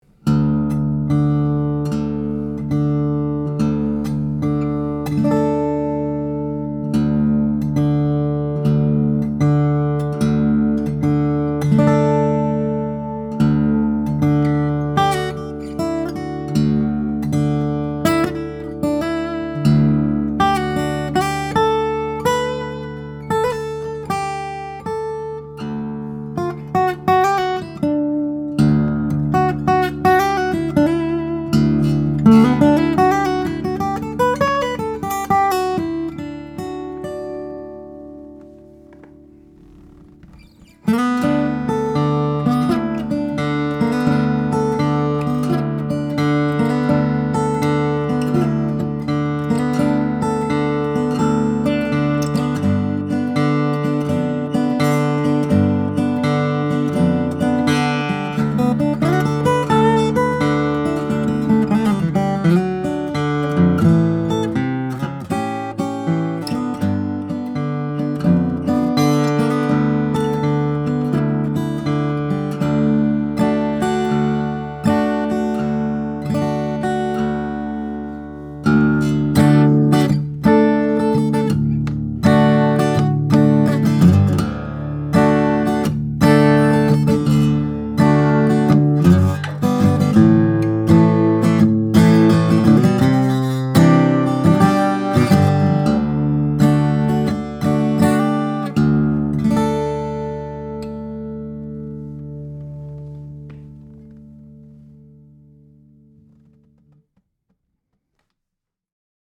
Brand New Bouchereau Mistral OM, Quilted Sapele/Sitka Spruce - Dream Guitars
Not only is this Mistral a knockout in Quilted Sapele and salvaged Sitka, it’s got the voice to match.